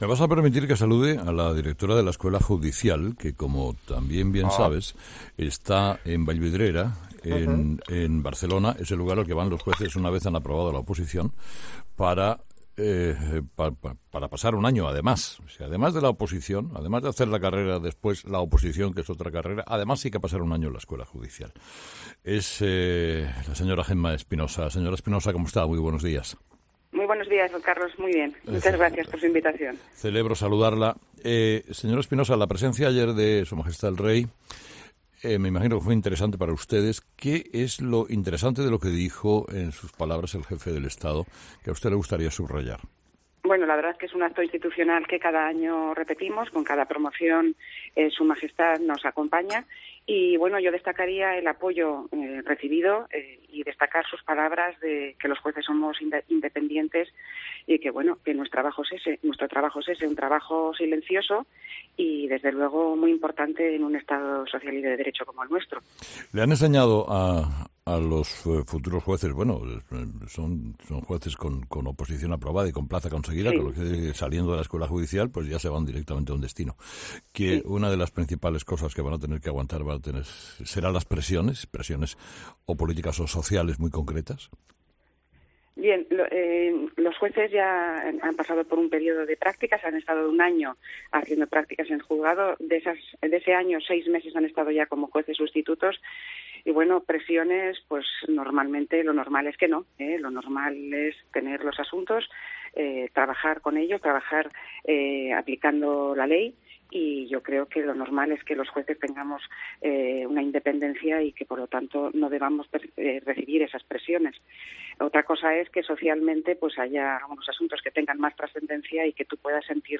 Gemma Espinosa, directora de la Escuela Judicial y esposa del juez Pablo Llarena, dice en su primera entrevista que "no" va tranquila a comprar el pan.